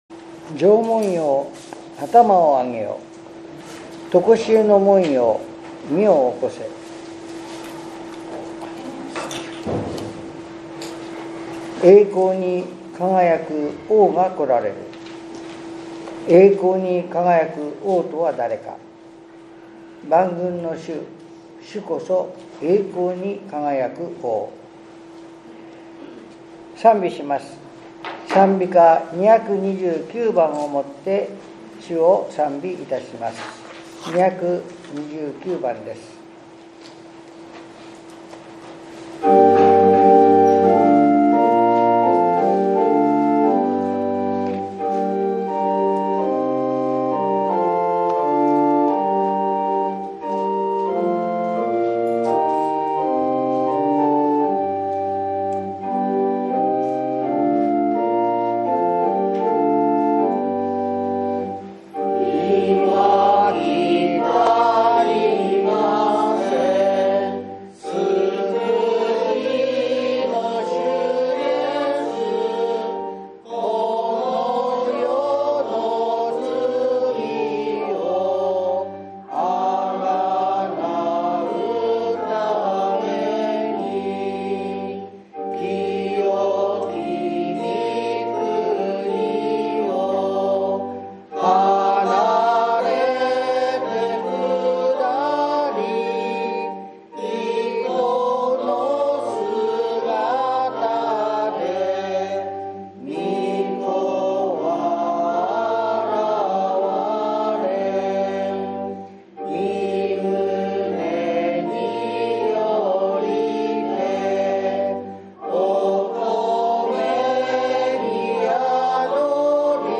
１１月３０日（日）降誕節第1主日礼拝